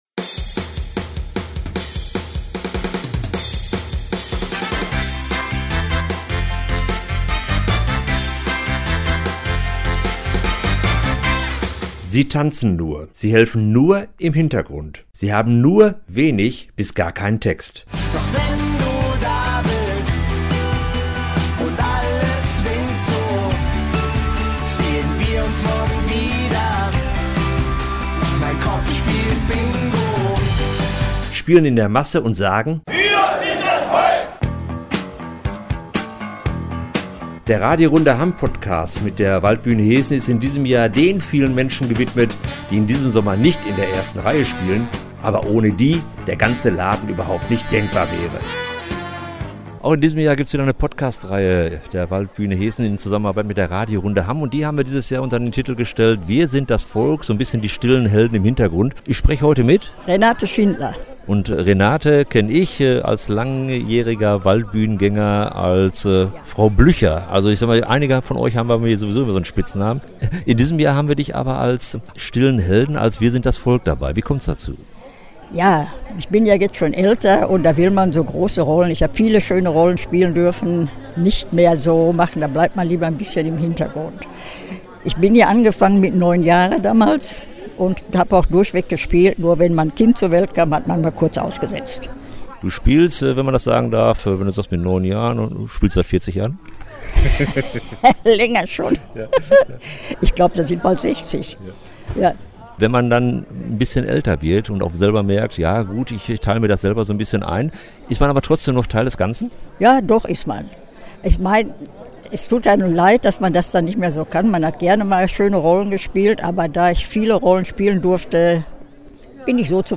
Neue Podcast-Reihe mit der Waldbühne Heessen - WIR SIND DAS VOLK! (Podcast verfügbar)